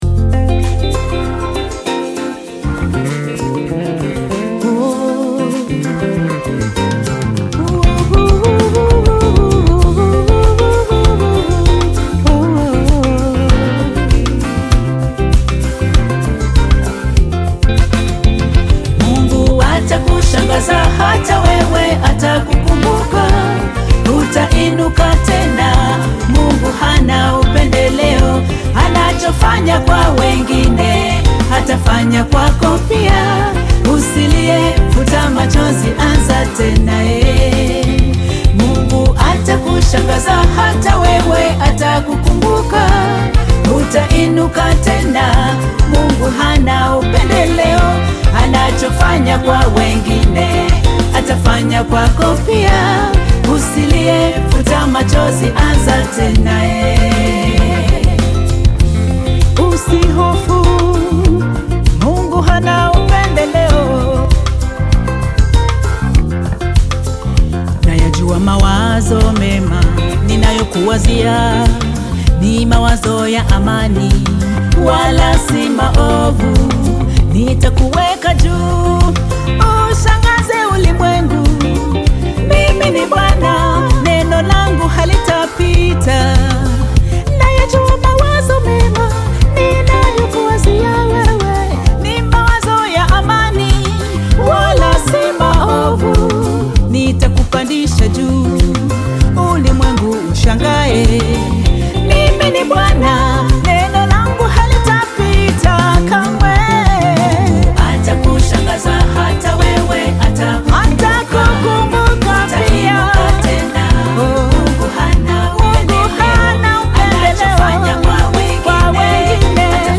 Prolific London-based gospel music minister